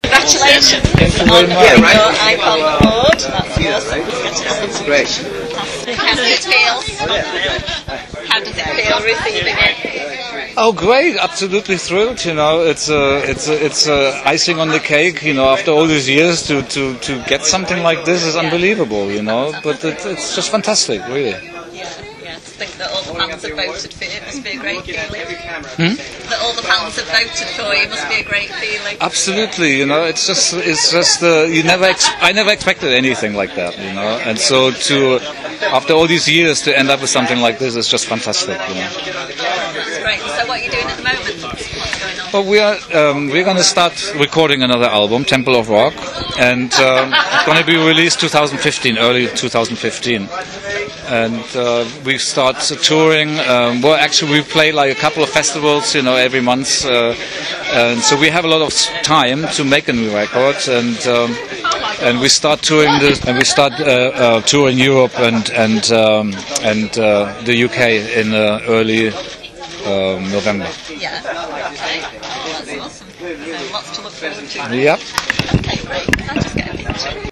During the busy evening in the media area at the Metal Hammer Golden God Awards this last Monday 16th June, I had no idea that I would be coming face to face with the guitar legend that is Michael Schenker.